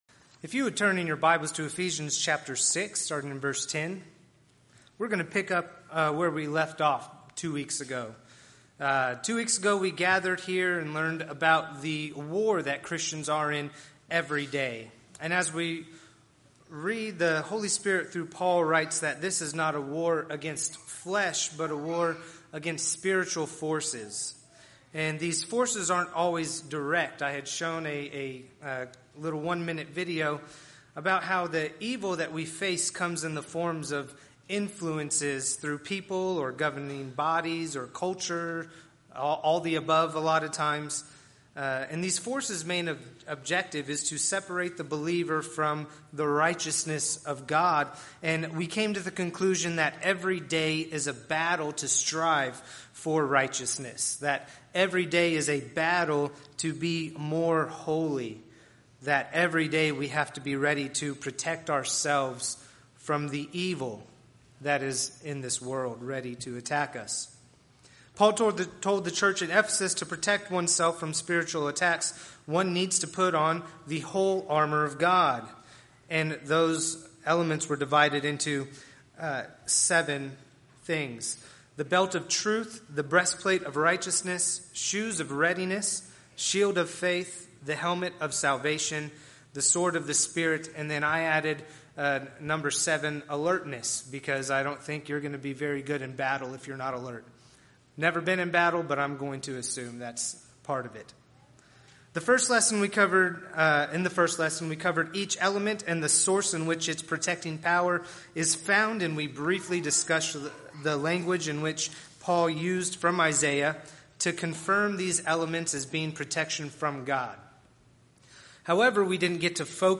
Podcasts Videos Series Sermons The Whole Armor of God